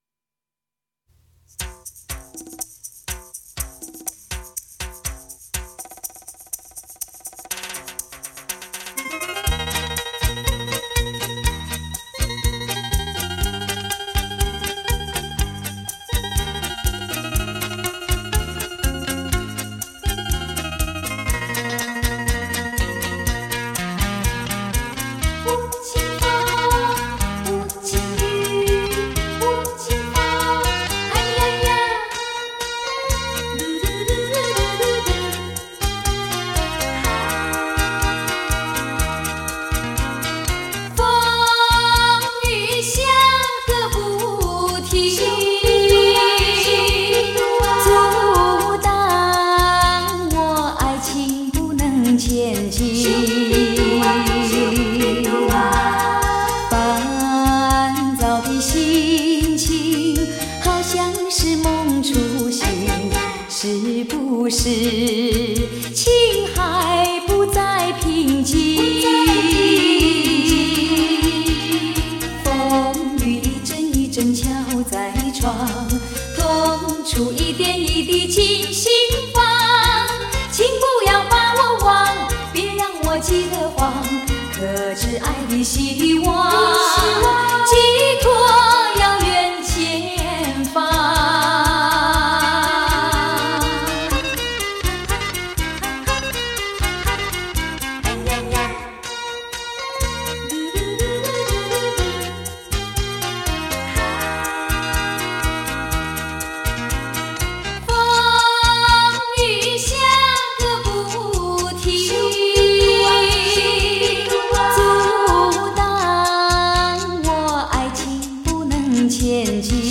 录音：台北乐韵录音室 广州国光录音棚